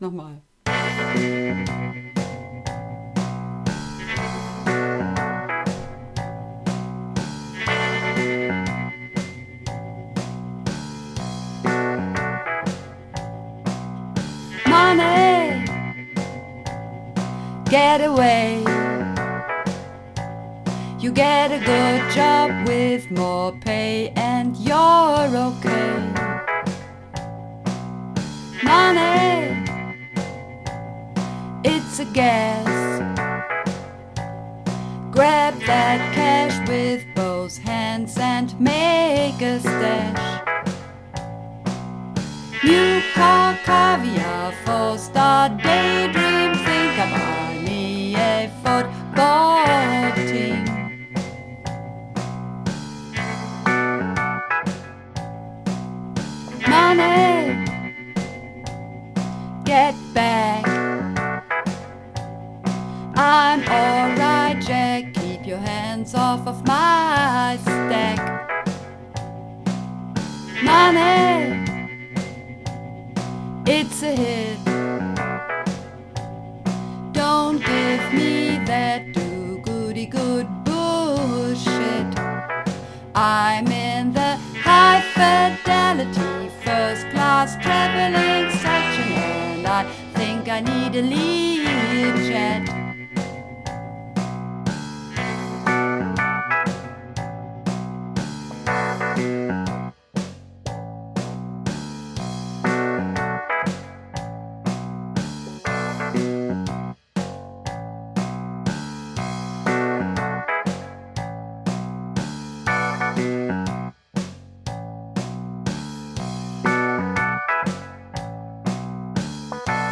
Übungsaufnahmen - Money
Money (Alt und Bass)
Money__1_Alt_Bass.mp3